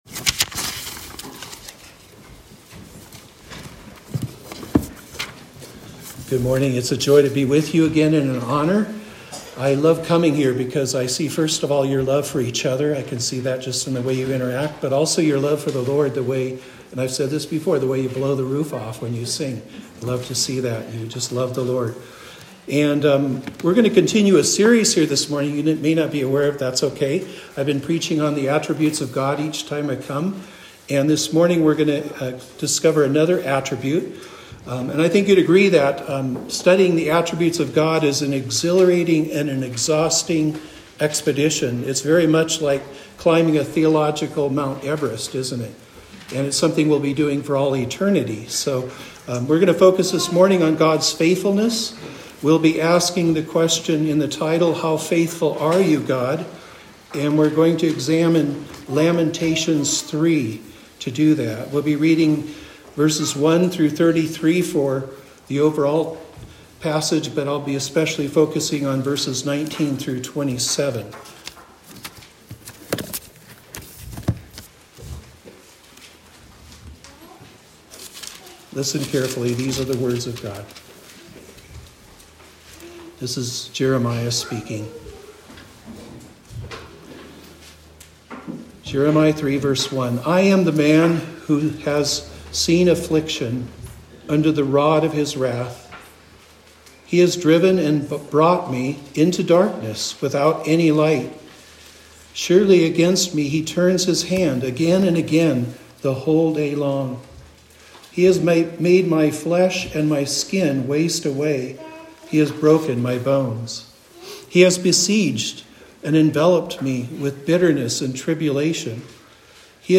Series: Guest Preacher
Lamentations 3:1-33 Service Type: Morning Service The laments of Jeremiah show forth the faithfulness of God